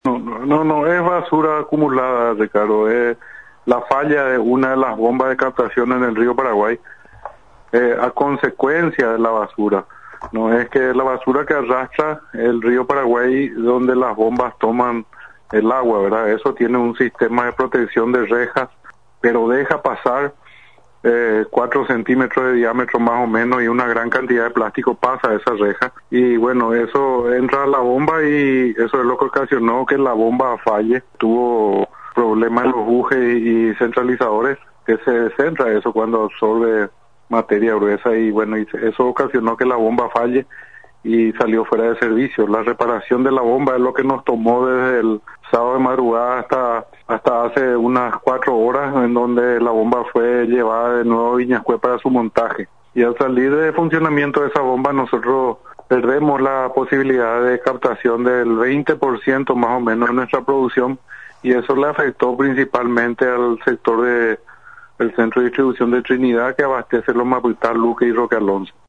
Fue reparada la bomba de agua de Viñas Cue, por lo que el servicio será reestablecido paulatinamente en los puntos afectados, mencionó Natalicio Chase Acosta. Presidente del Directorio de la ESSAP S.A en comunicación con Radio Nacional.